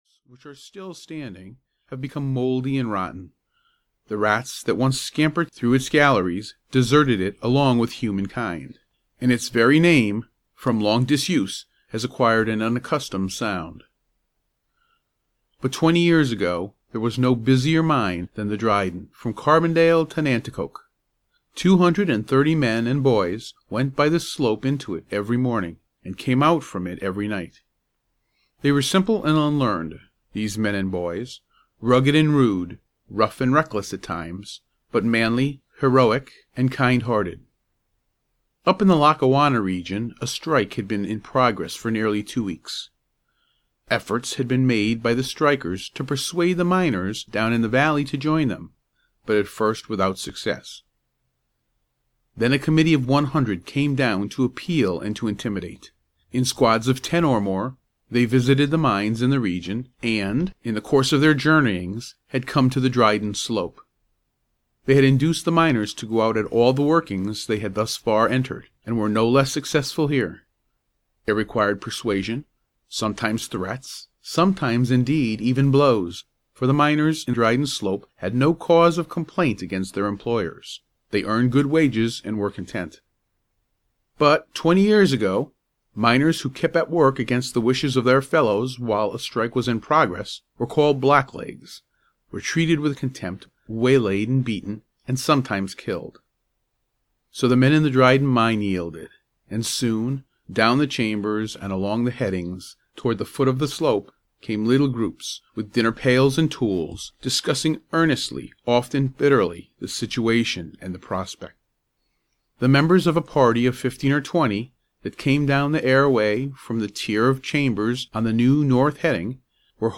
The Blind Brother (EN) audiokniha
Ukázka z knihy